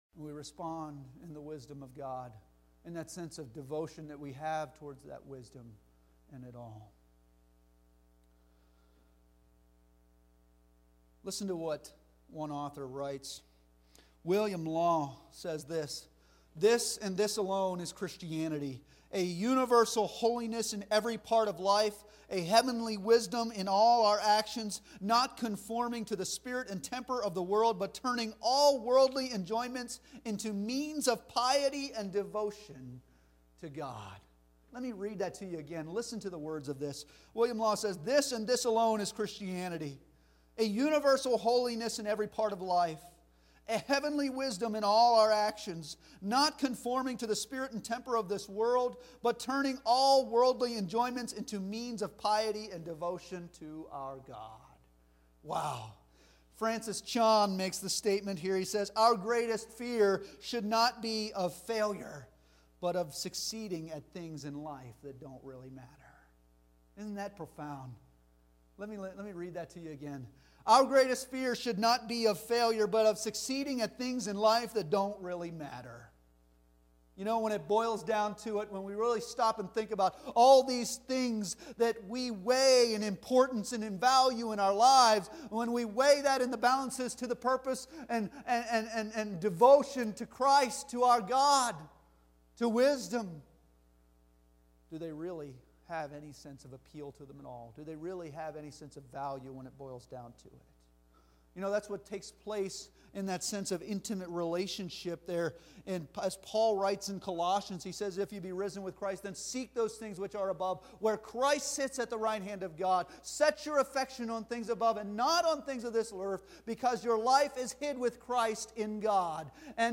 5-8 Service Type: Sunday Morning Worship Bible Text